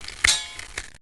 Heroes3_-_Skeleton_-_DefendSound.ogg